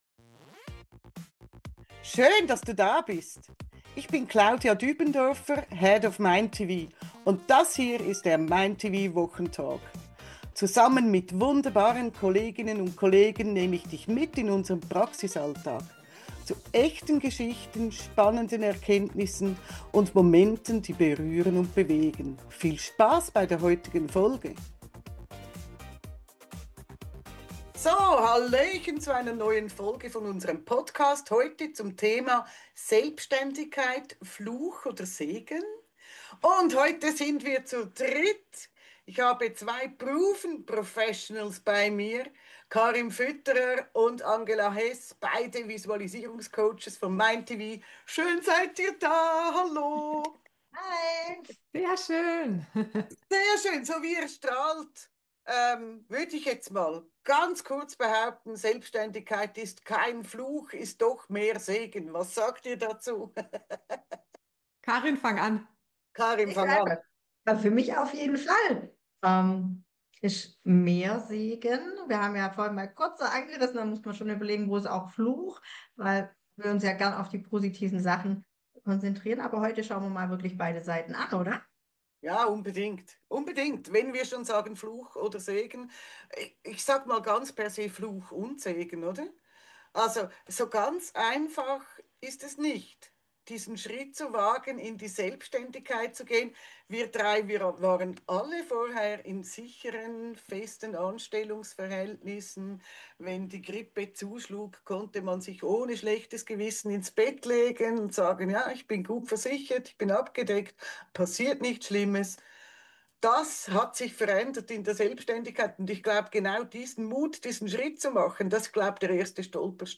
Sie erzählen Geschichten, geben Einblicke, beantworten Fragen und erläutern dir konkret, auf welche Antworten sie gestossen sind.